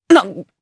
Lucias-Vox_Damage_jp_01.wav